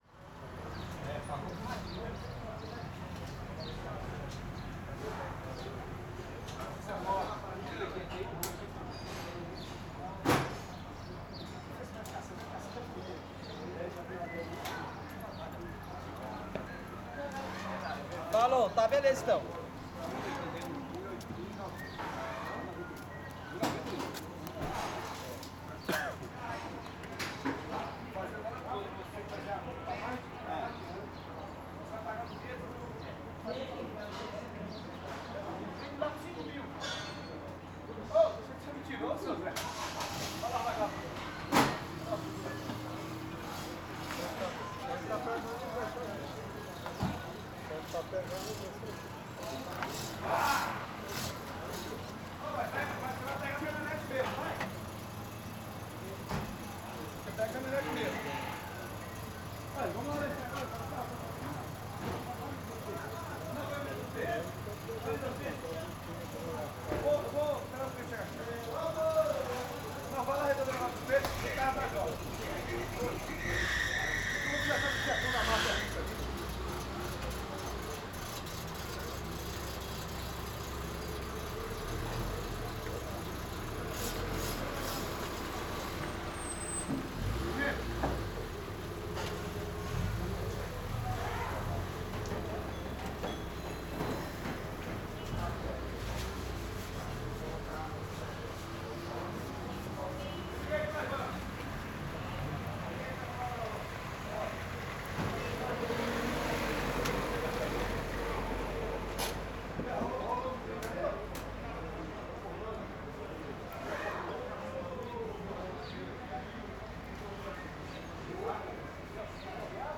Oficina mecanica sons metalicos vozerio carro acelerando esfregando Carro , Freio , Motor acelera desacelera , Oficina de carro , Sons de metal , Vozes masculinas Brasília , Ceilândia Stereo
CSC-04-179-OL- Oficina mecanica sons metalicos vozerio carro acelerando esfregando.wav